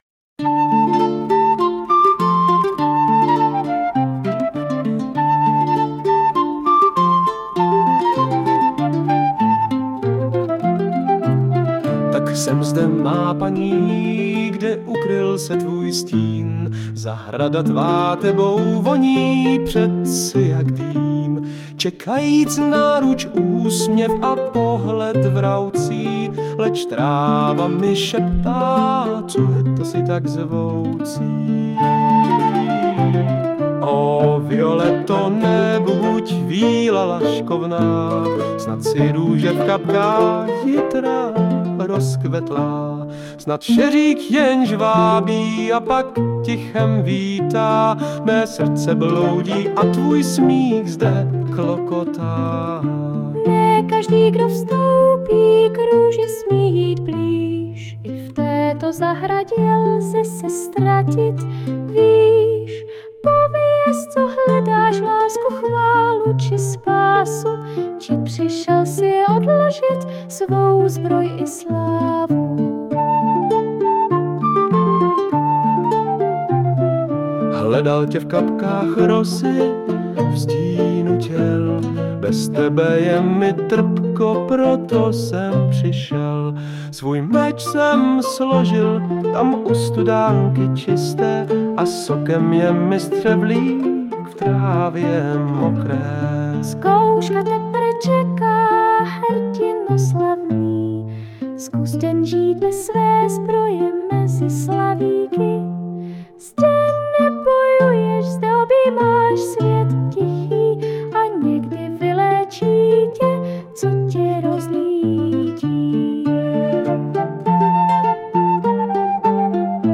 Balady, romance » Historické
No... to je problém toho AI zhudebnění, že se popere s čimkoliv, co mu předlozíš, a nemusí to vůbec být text pro písničku vhodný... zkus mu nabídnout třeba záznam diskuze ze sněmovny...